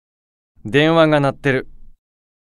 Beelzebub_Call_Notification_Voice.ogg.mp3